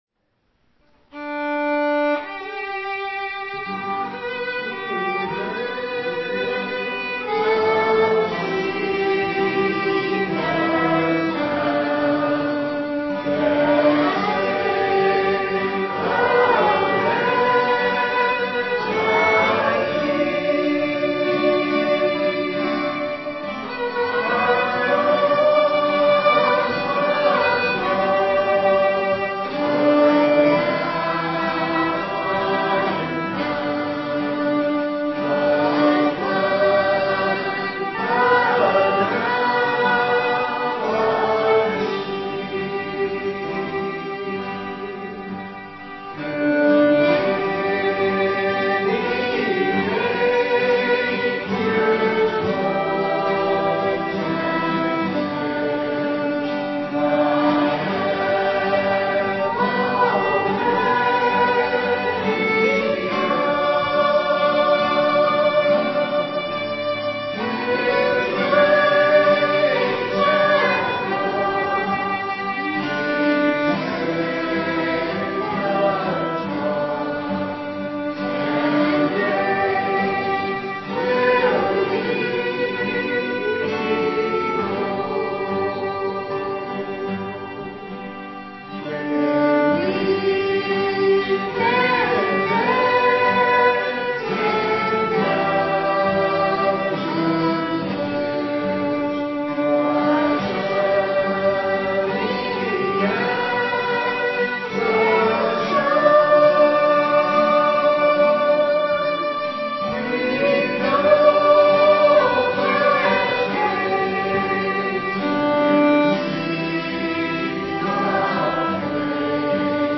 Fifth Sunday Night Sing
Message